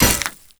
SPLAT_Generic_08_mono.wav